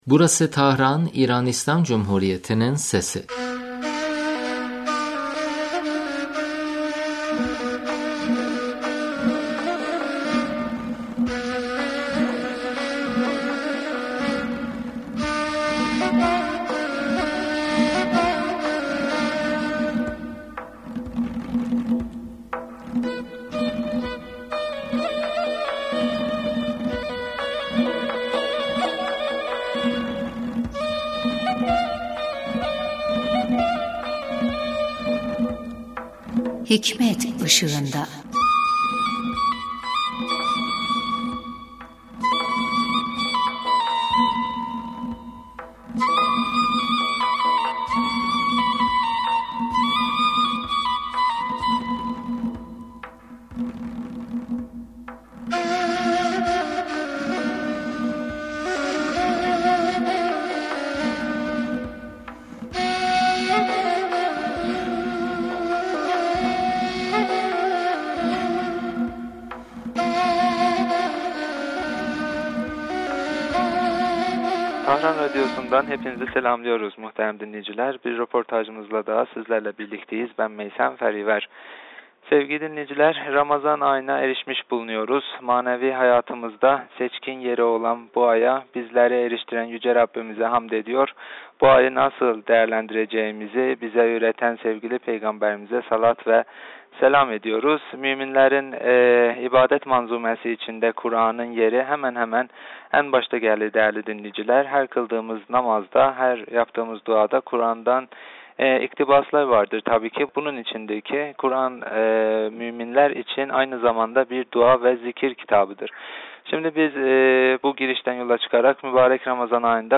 söyleşimiz
yaptığımız telefon görüşmesinde Ramazan ayında kuran okumanın fazileti hakkında konuştuk